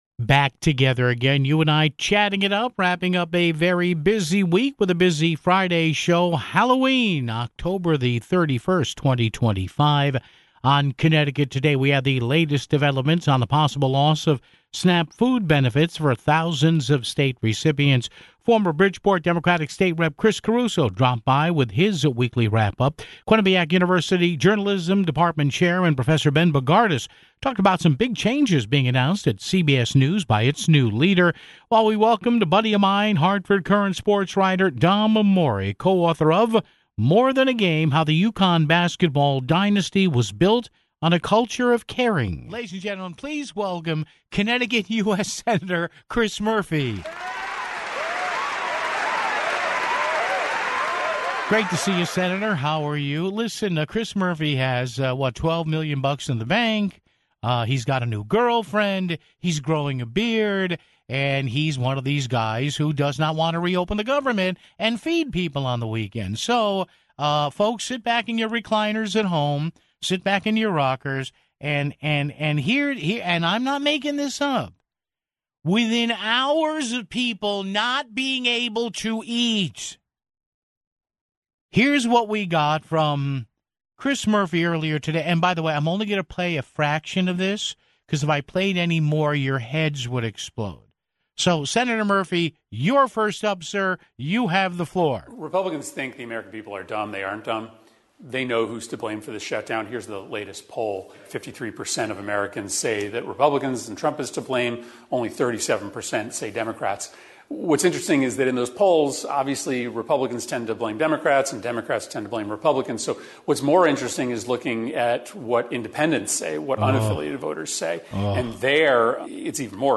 Former Bridgeport State Rep. Chris Caruso dropped by with his weekly wrap-up (12:58).